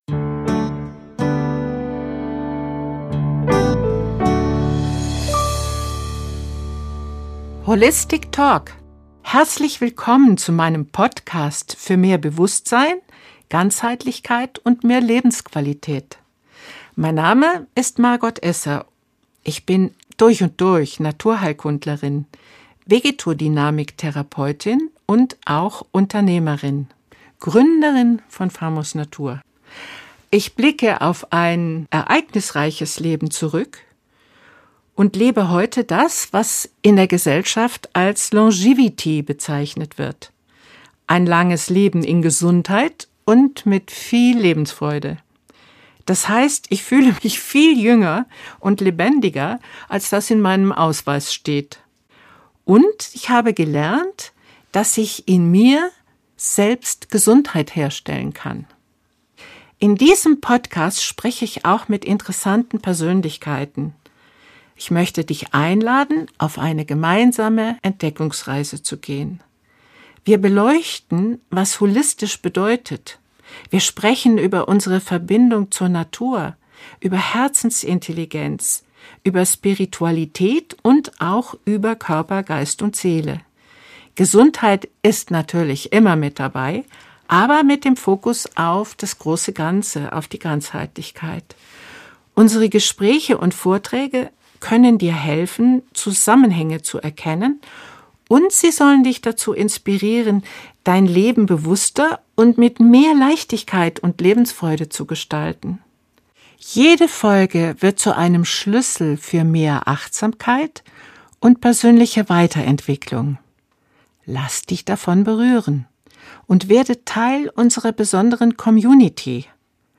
Im Gespräch wird jedoch schnell spürbar, wie konkret dieses Thema ist. Es betrifft das, was uns jeden Tag trägt und versorgt: Wasser, Erde, Pflanzen, Wälder, Lebensmittel und die natürlichen Kreisläufe, auf die wir angewiesen sind.